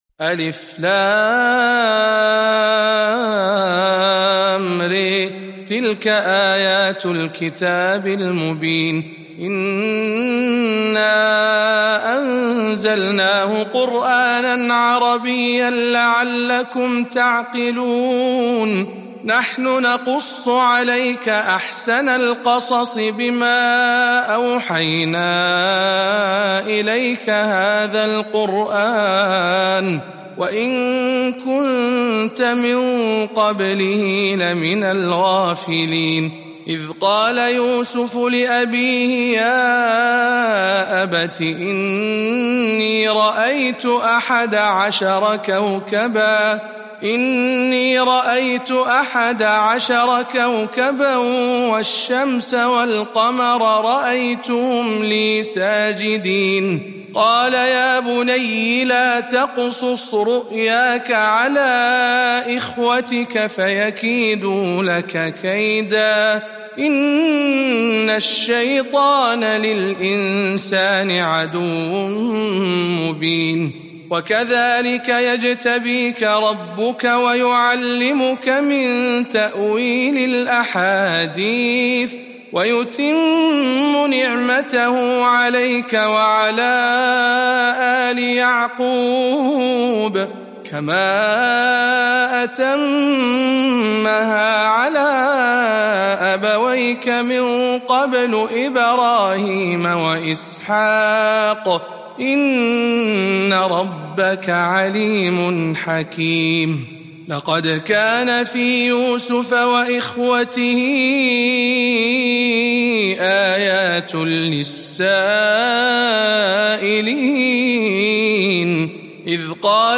سورة يوسف برواية شعبة عن عاصم